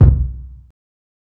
KICK_POCKET_KILLER.wav